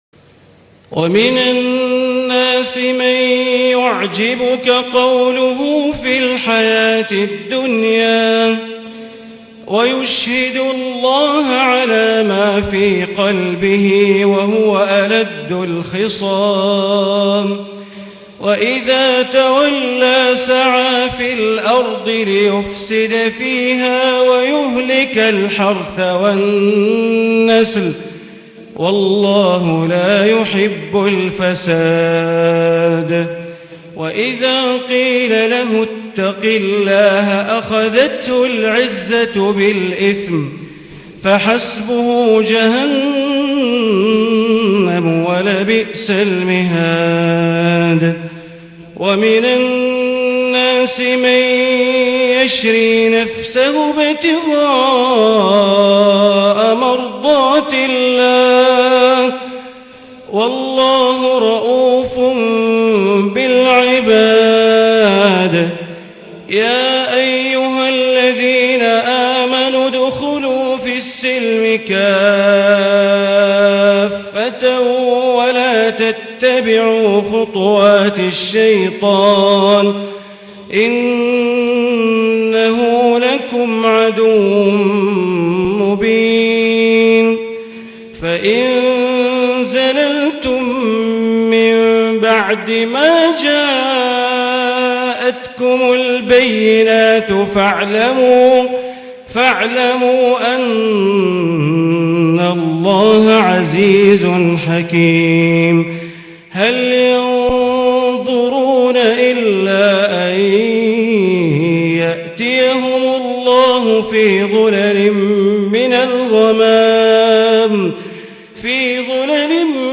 Isha 7-8-2017 prayer from surat Al-Baqara > 1438 > Prayers - Bandar Baleela Recitations